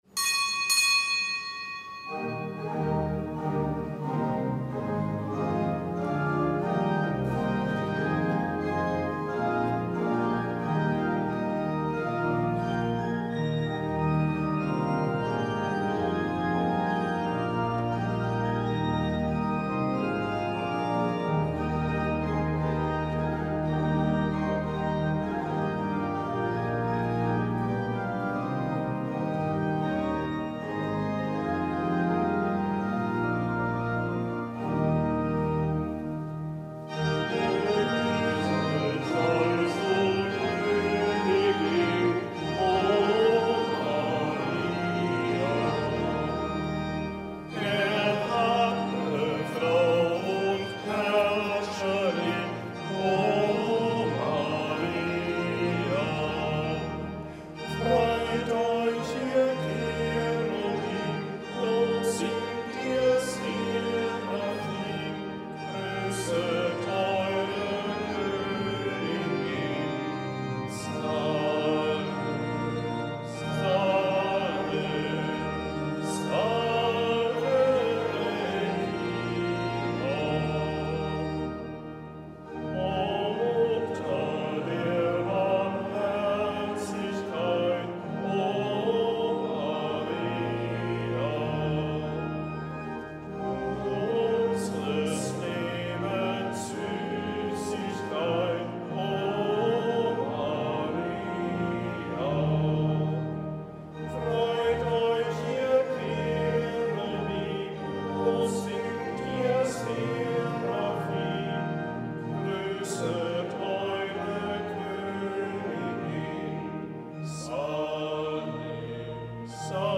Kapitelsmesse am Samstag der fünfzehnten Woche im Jahreskreis
Kapitelsmesse aus dem Kölner Dom am Samstag der fünfzehnten Woche im Jahreskreis, die als nichtgebotener Gedenktag als Marien-Samstag gefeiert wurde.